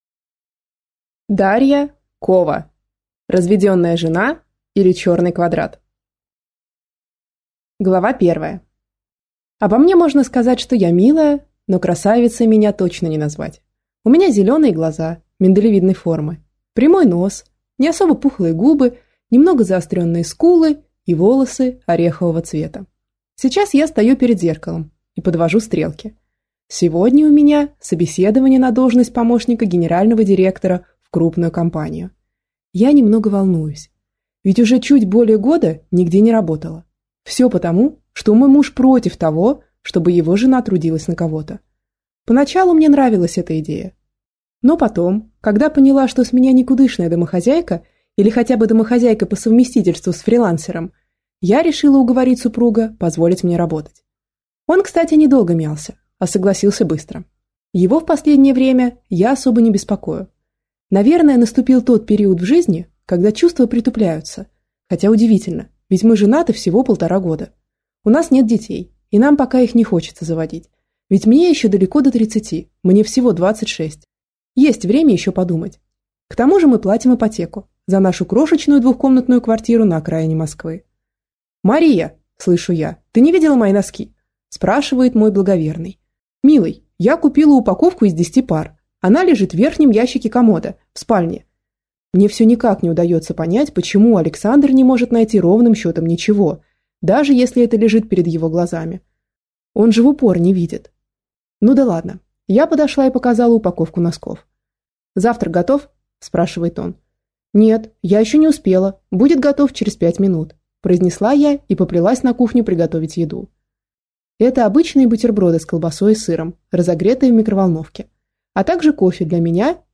Аудиокнига Разведенная жена | Библиотека аудиокниг